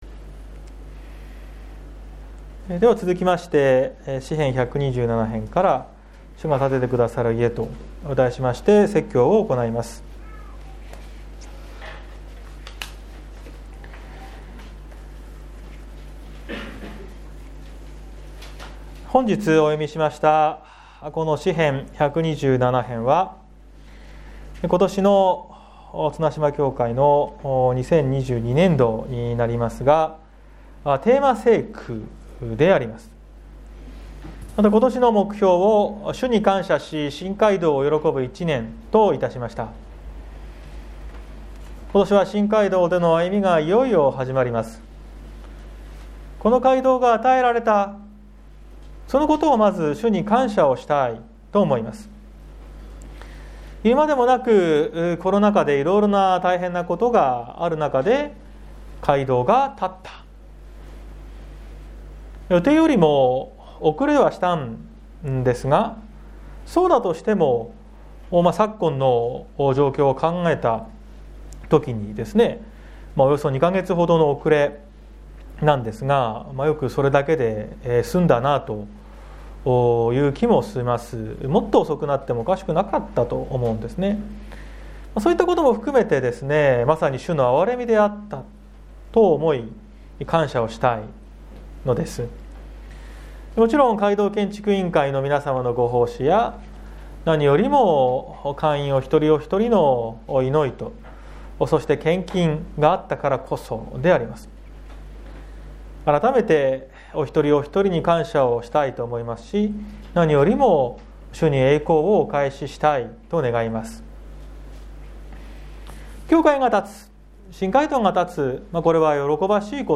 2022年01月30日朝の礼拝「主が建ててくださる家」綱島教会
説教アーカイブ。